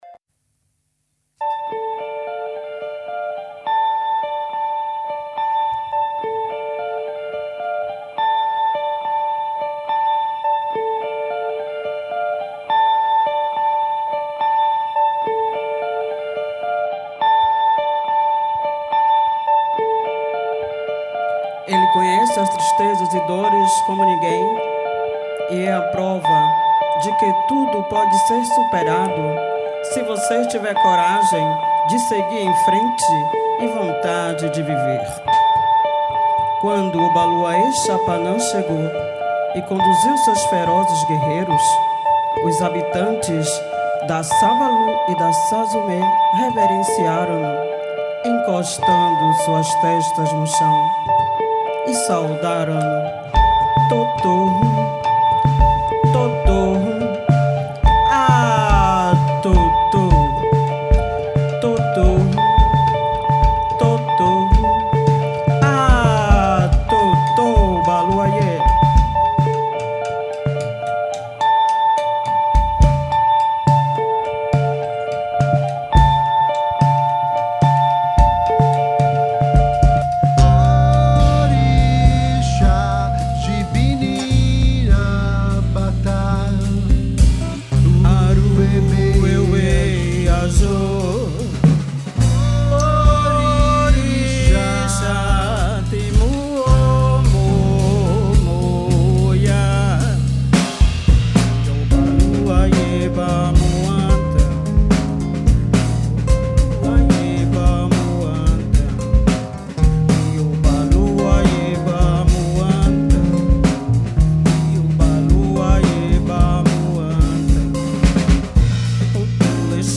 Ogni giovedì alle 21.30, l’auditorium Demetrio Stratos di Radio Popolare ospita concerti, presentazioni di libri, reading e serate speciali aperte al pubblico.